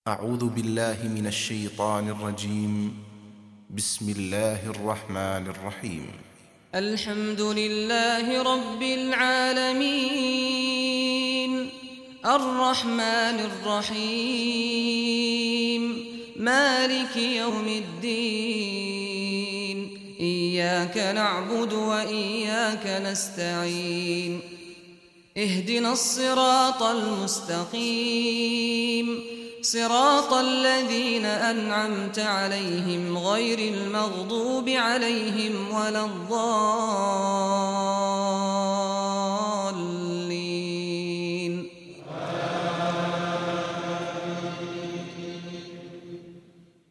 Surah Fatiha Recitation by Sheikh Saad al Ghamdi
Surah Fatiha, listen or play online mp3 tilawat / recitation in Arabic in the beautiful voice of Sheikh Saad al Ghamdi.